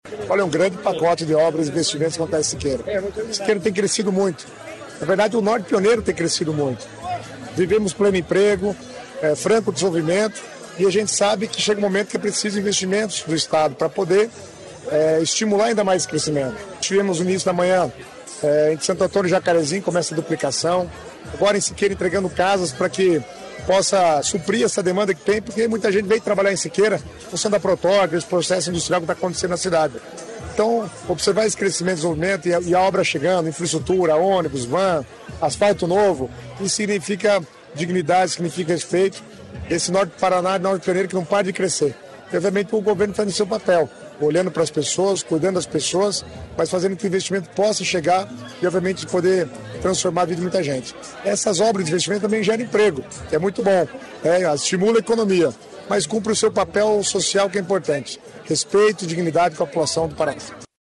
Sonora do secretário das Cidades, Guto Silva, sobre os investimentos em Siqueira Campos | Governo do Estado do Paraná